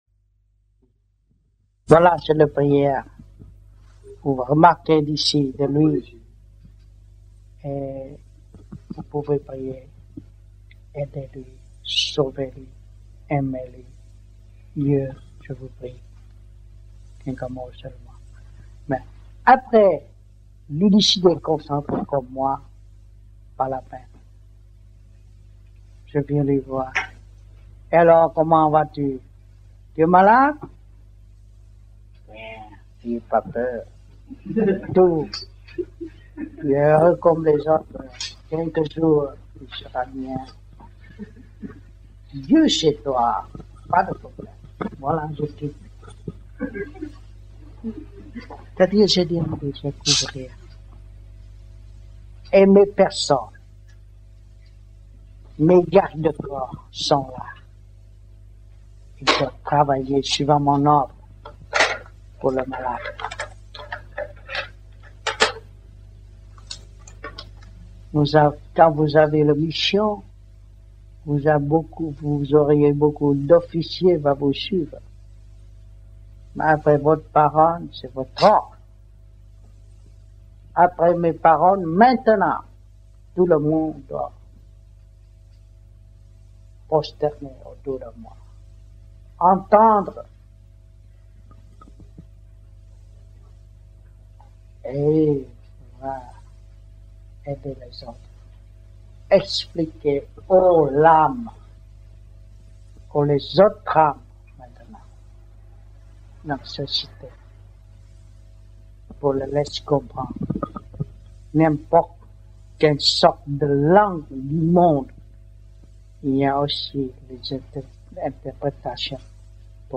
1980 Đàm Đạo
1980-12-13 - GIEN - THẦY ĐÀM ĐẠO TẠI NHÀ THƯƠNG GIEN